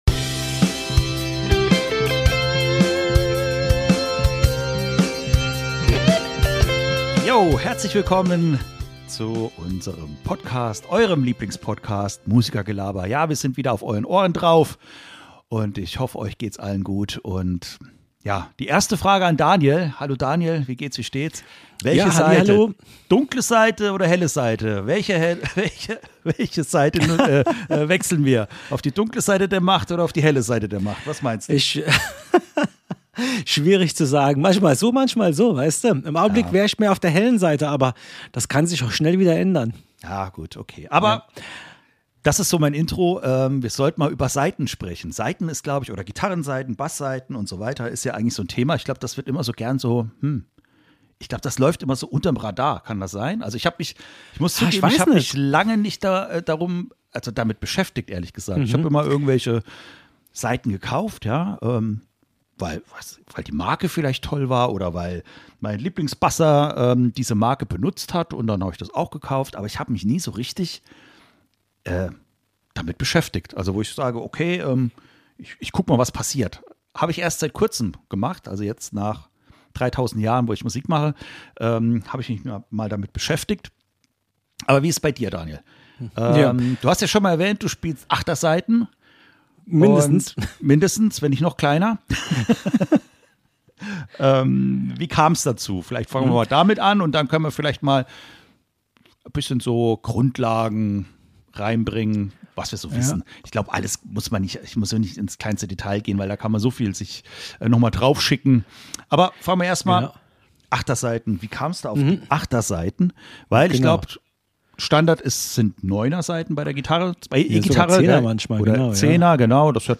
In dieser Folge geht es mal um Saiten. Ein nettes Gespräch über ein unscheinbares aber wichtiges Element am Instrument.